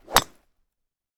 Sfx_golfswing_03.ogg